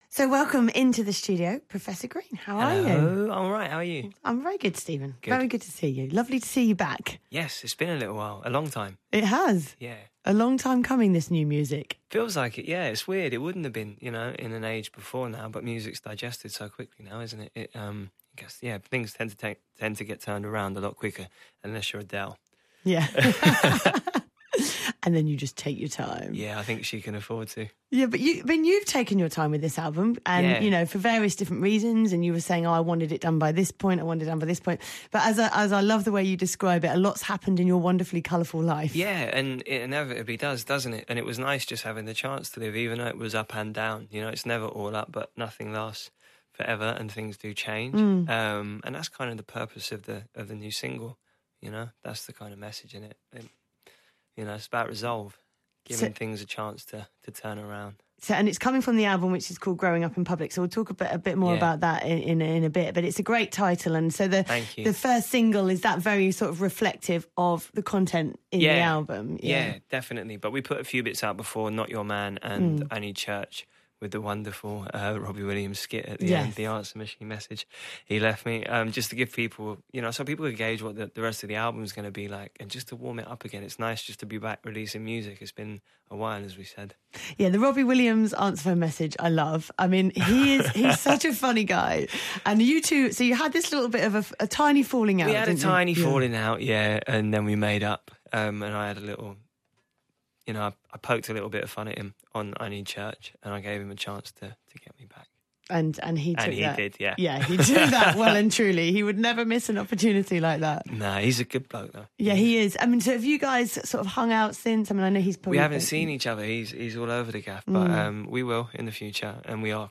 Professor Green Interview